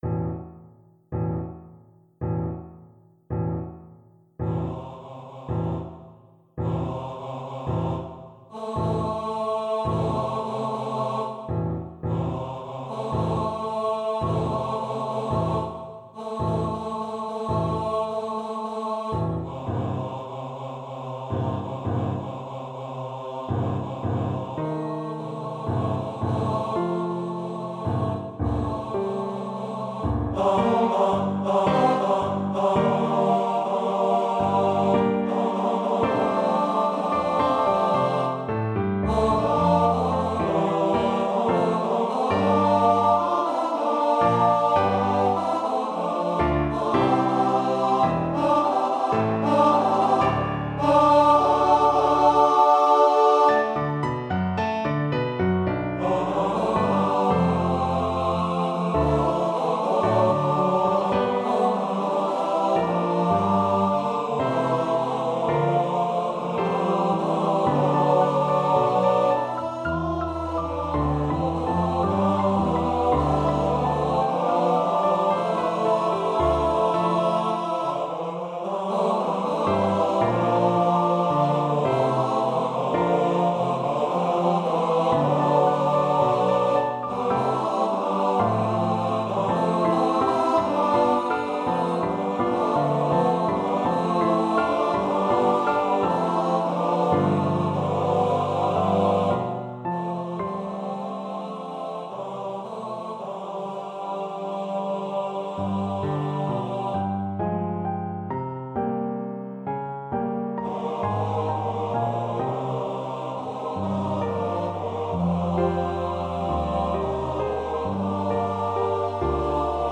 for TTBB and Piano